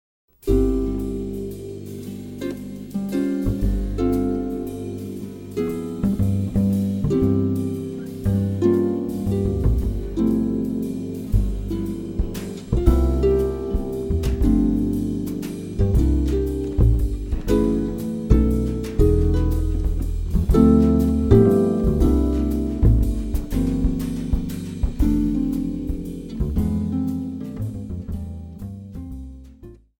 harp
bass
drums